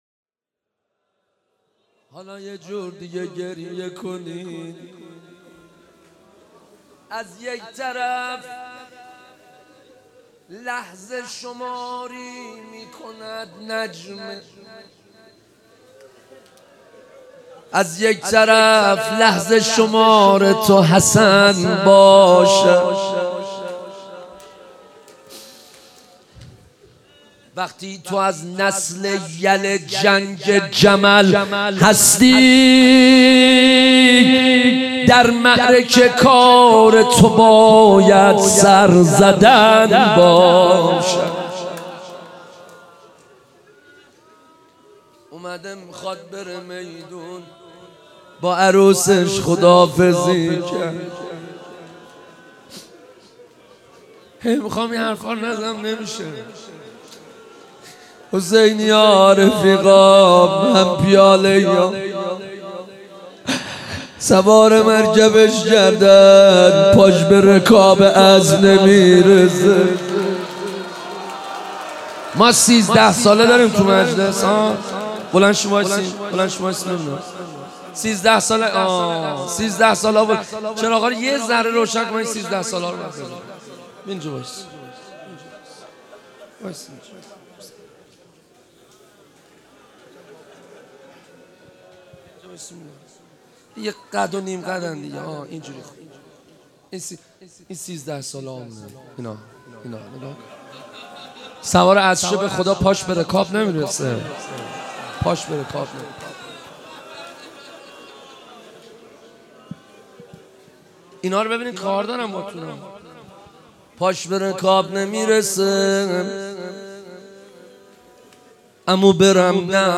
روضه حضرت قاسم